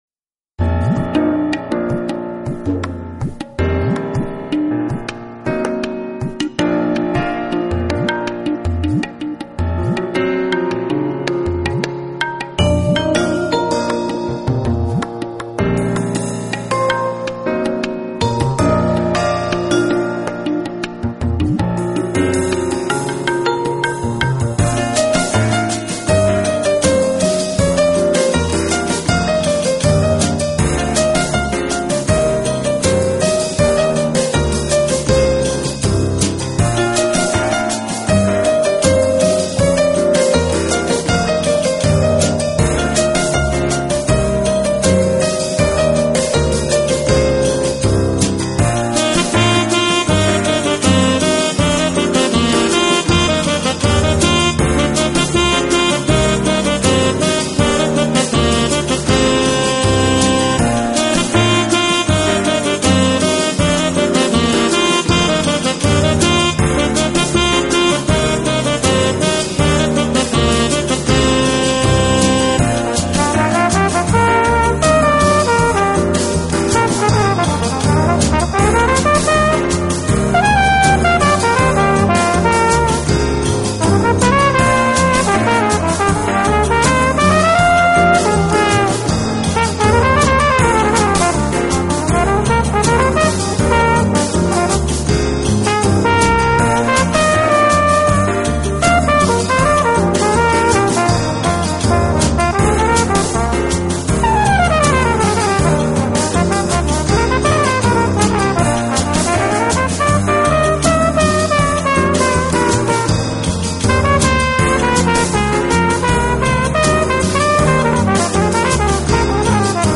Genre: Instrumantal